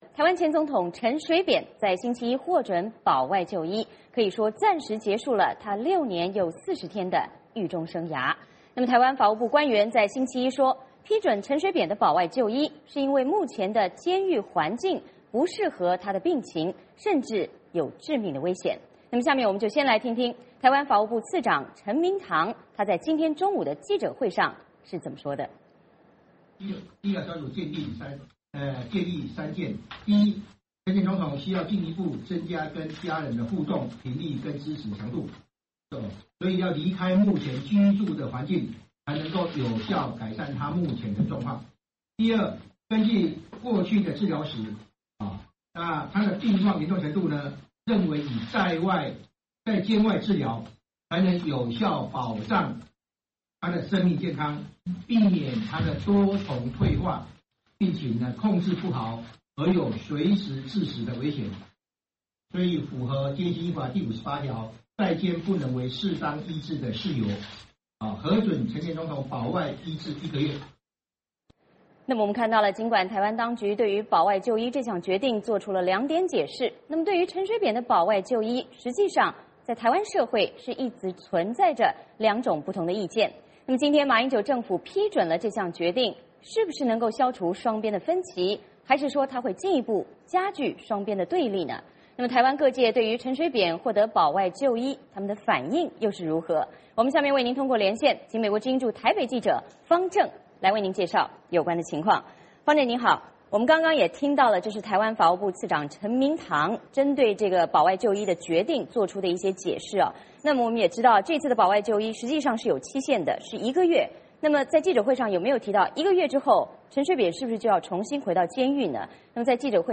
VOA连线：陈水扁出狱，保外就医争议仍未停歇